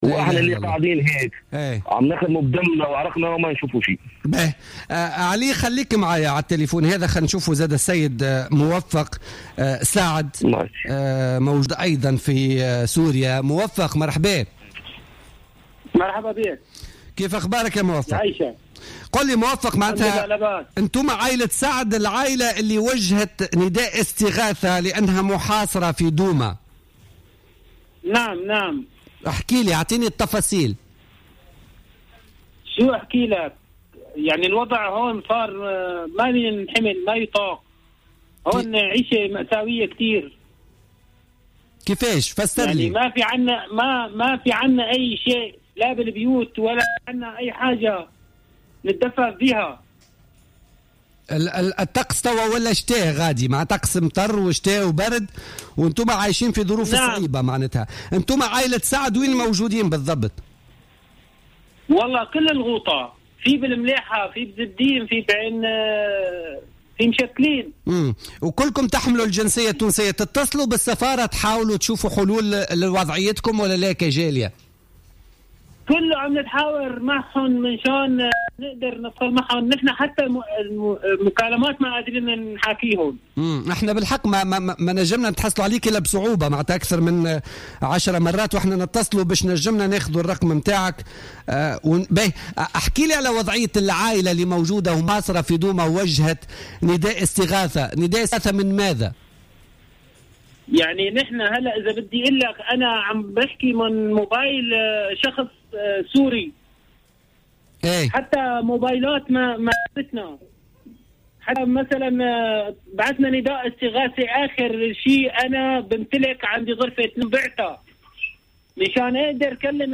est intervenu ce jeudi 5 novembre 2015 sur les ondes de Jawhara FM dans le cadre de l’émission Politica.